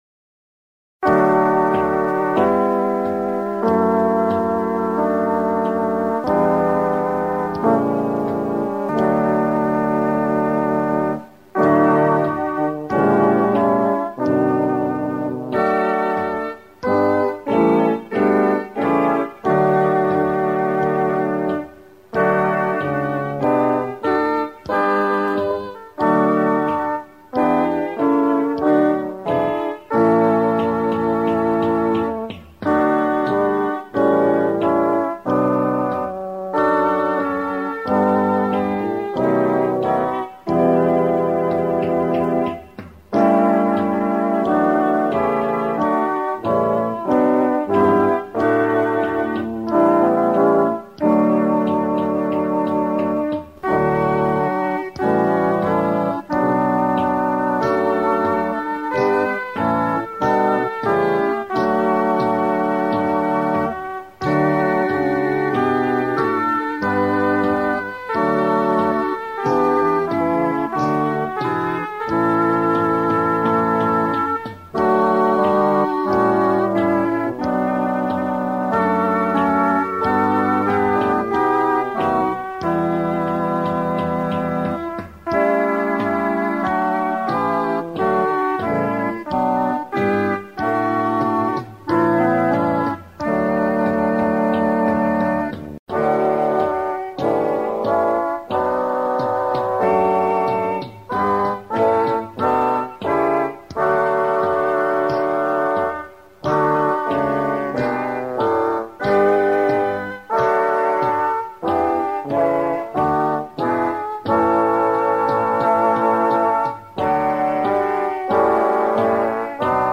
[This accompaniment includes a prelude]